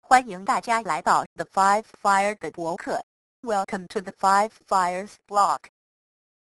C# sapi将文本转换为声音文件
文字变声音